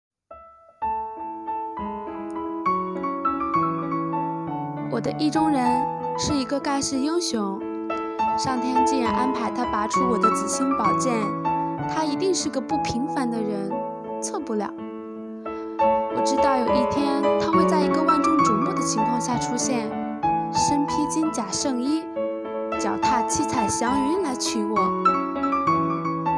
“阅读的力量--读给你听”主题朗诵